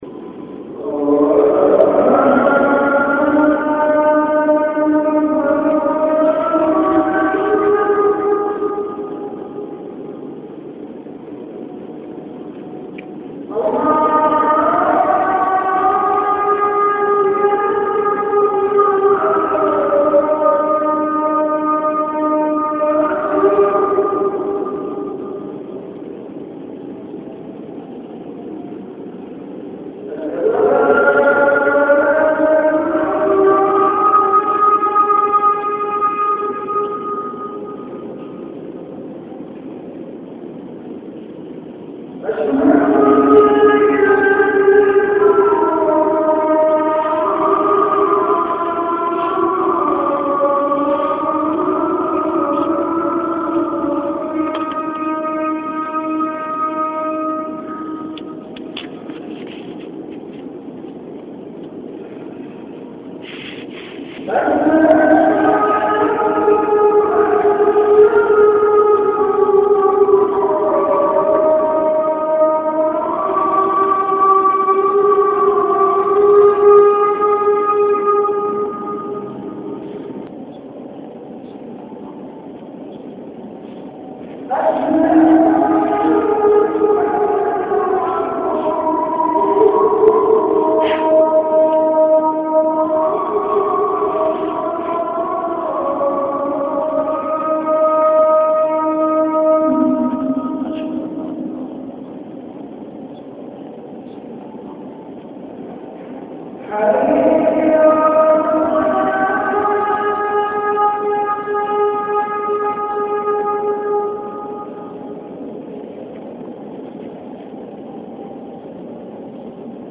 Azan · Jamia Masjid Bait-ul-Mukkaram, Karachi
CategoryAzan
VenueJamia Masjid Bait-ul-Mukkaram, Karachi